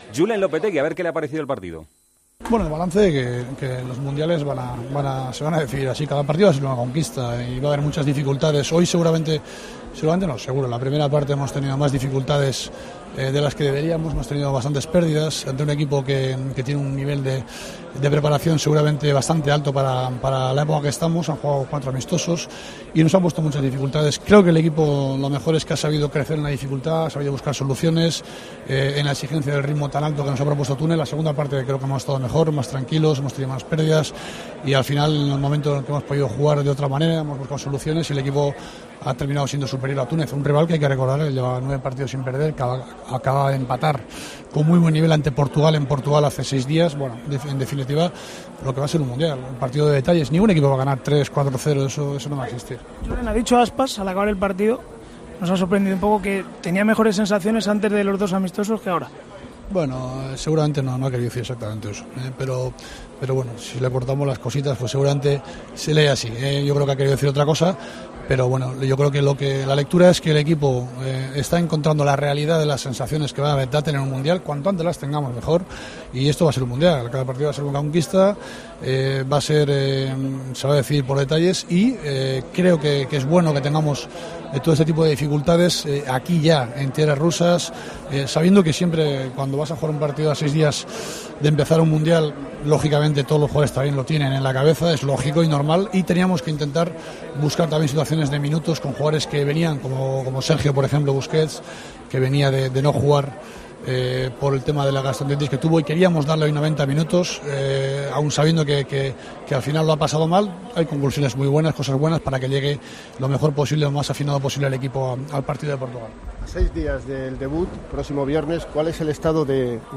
El seleccionador nacional ha hablado con los medios después del último encuentro amistoso previo al Mundial: "No creo que Aspas haya querido decir exactamente eso.